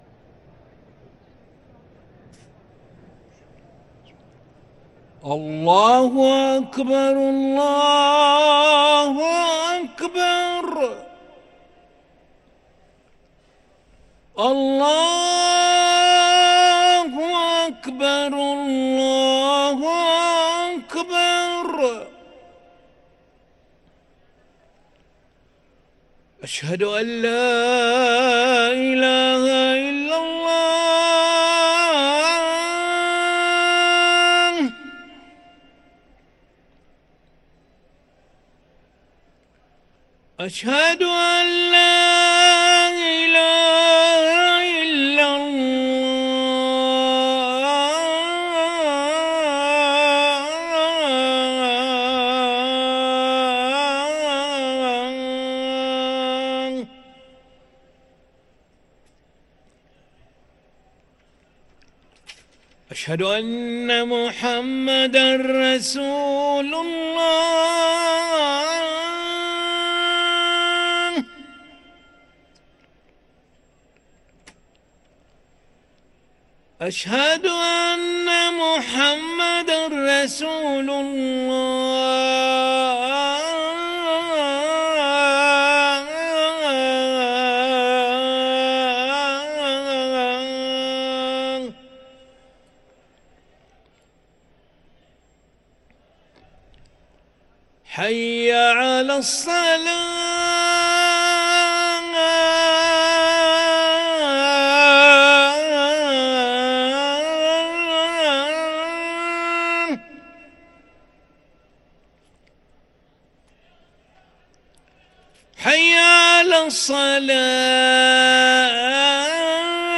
أذان العشاء للمؤذن علي ملا الخميس 1 رمضان 1444هـ > ١٤٤٤ 🕋 > ركن الأذان 🕋 > المزيد - تلاوات الحرمين